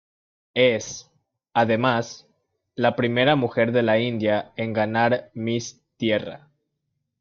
Pronounced as (IPA) /ɡaˈnaɾ/